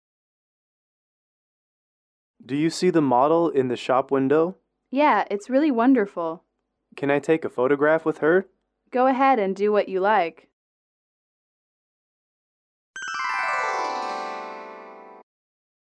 英语口语情景短对话08-1：合影留念